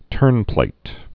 (tûrnplāt)